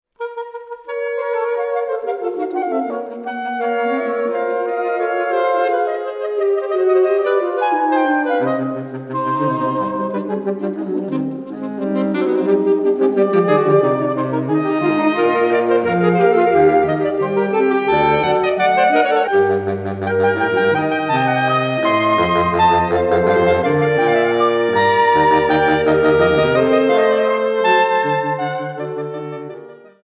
4 Saxophones (SATB)